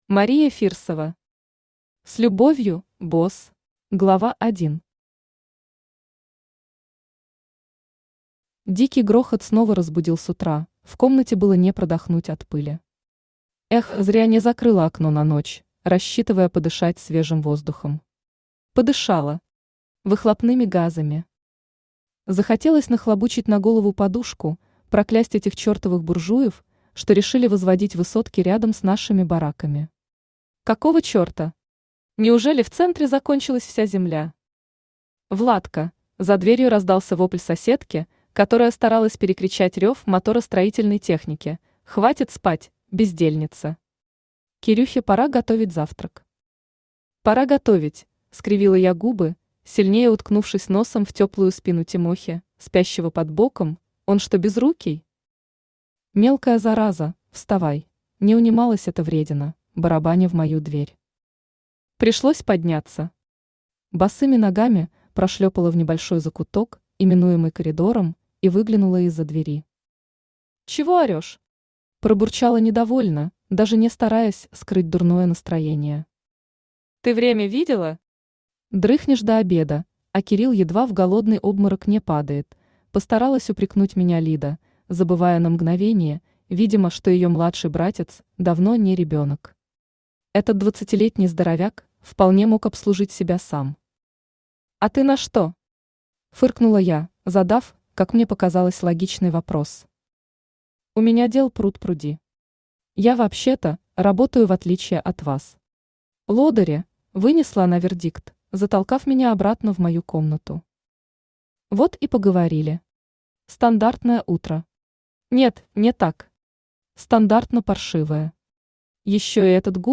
Аудиокнига С любовью, босс | Библиотека аудиокниг
Aудиокнига С любовью, босс Автор Мария Фирсова Читает аудиокнигу Авточтец ЛитРес.